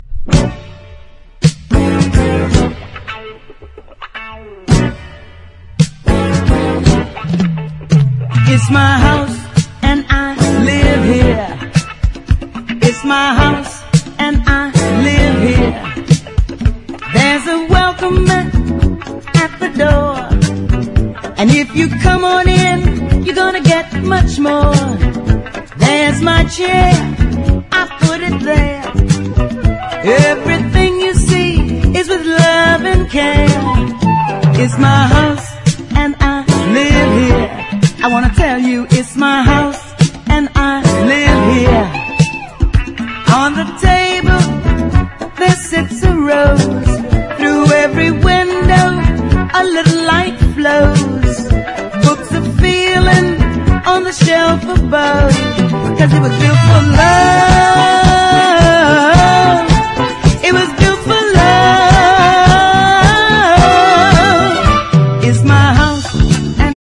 SOUL / SOUL / FREE SOUL / FRENCH / CANADIAN
DJも必聴のグルーヴィーなフレンチ・カナディアン・レア・オムニバス！